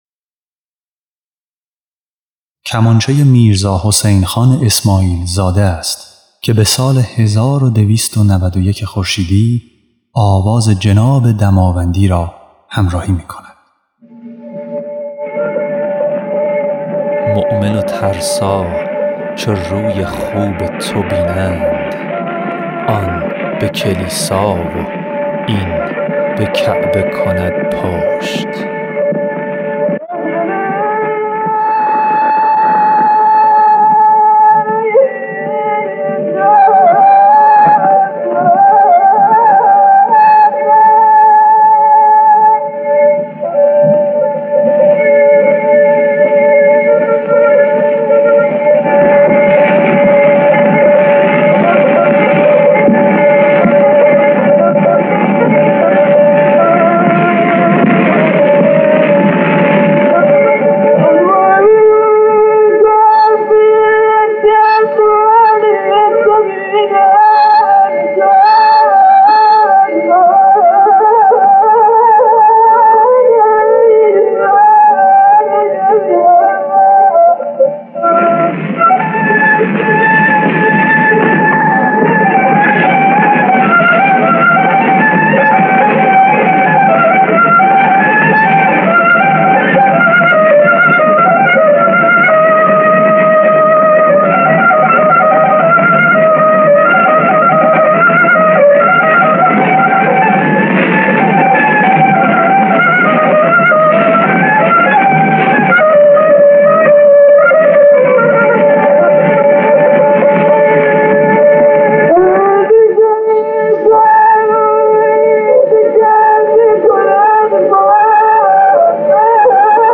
نوازنده کمانچه
خواننده
ضبط صدا در آن روزگار با سوزن‌ های فولادی، دیسک‌ های مومی شکننده و شیپورهایی بزرگ انجام می‌شد و کوچک‌ ترین صدای اضافه یا خطا می‌ توانست ضبط را خراب کند. اما همین فضای محدود و ابتدایی، موجب تمرکز و صمیمیتی شد که در نتیجه نهایی شنیده می‌ شود.
سکوت‌های کوتاه و فکر شده در میان جمله‌ ها، تحریرهای مسلسل وار و طولانی، همچنین پرداختن به تزیینات صدا و دینامیک صوتی بالای استاد دماوندی، باعث شده تا شنونده نه‌ فقط به کلام توجه کند، بلکه فضای عاطفی نهفته در آن را نیز با تمام وجود احساس کند. حسین‌ خان اسماعیل‌ زاده نیز با کمانچه خود این فراز و فرود ها را تقویت می‌ کند؛ گاهی با آرشه‌ های نرم و ممتد، گاهی با جملات کوتاه و تند، و گاهی با پاسخ فی البداهه ظریفی که رنگ تازه‌ای به قطعه می‌ بخشد.
صفحه گرامافون آواز دشتی 2 جناب دماوندی که در سال 1291 خورشیدی ضبط شد